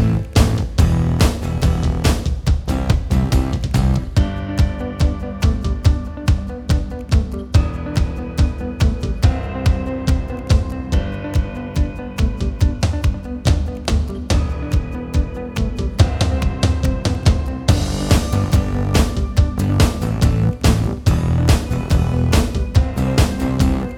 Minus Main Guitar Pop (2010s) 3:35 Buy £1.50